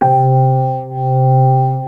B3-ORGAN 3.wav